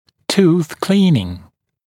[tuːθ ‘kliːnɪŋ][ту:с ‘кли:нин]чистка зуба